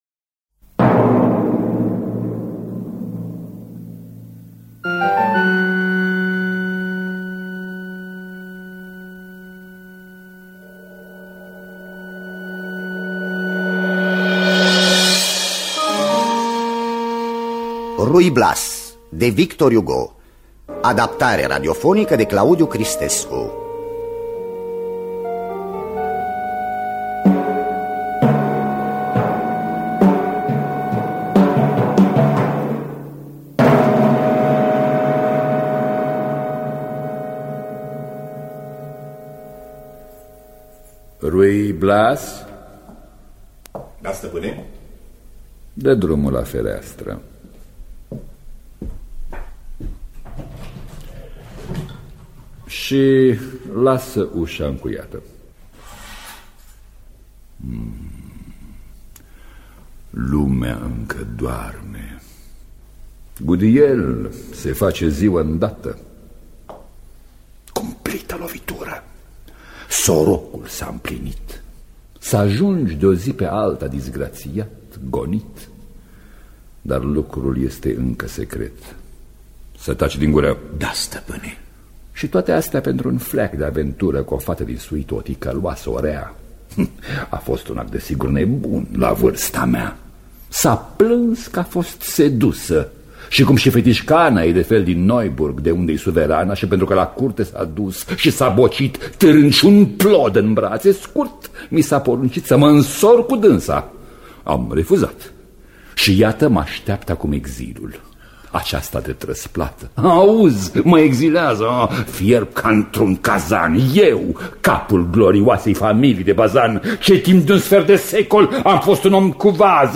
Ruy Blas de Victor Hugo – Teatru Radiofonic Online